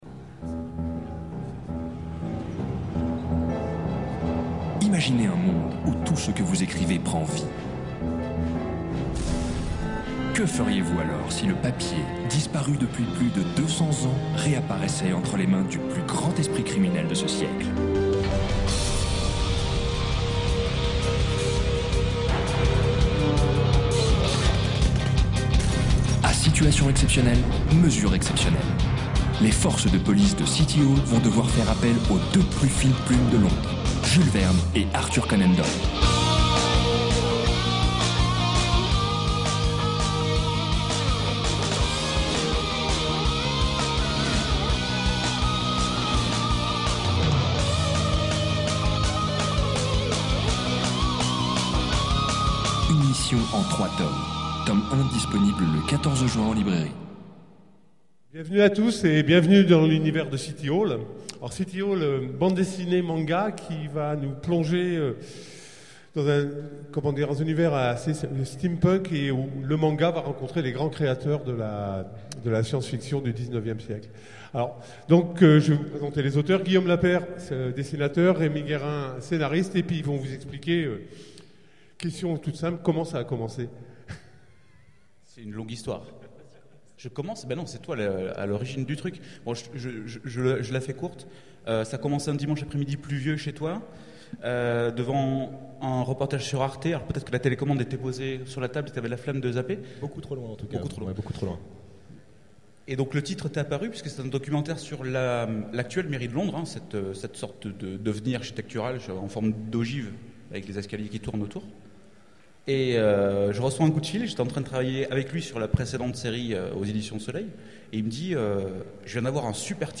Utopiales 12 : Conférence City Hall